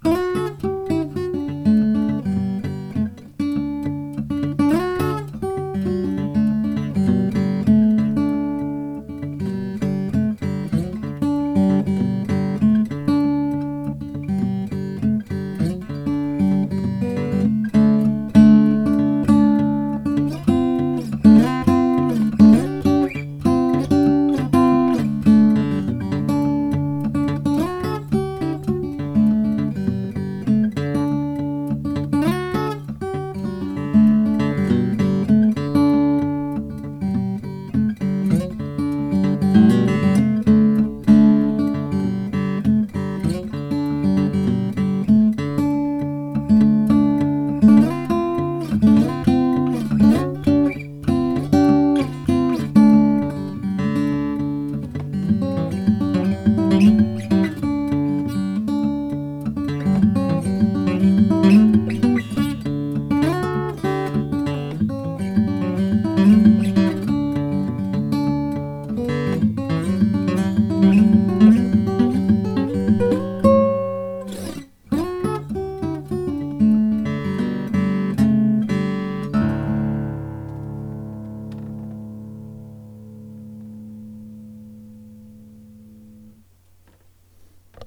Jumbo Cedar/Padouk with fallaway cutaway NEW SOUNDS!
Cedar top
Padouk Back/sides
Recorded with condensor microphones, without effects
The guitar sounds like it has a great deep voice.